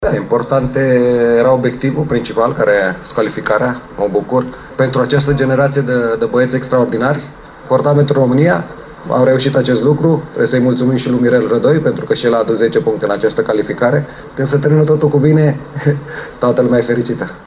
Antrenorul lor, Adrian Mutu, i-a mulțumit pentru munca depusă în trecut și lui Mirel Rădoi: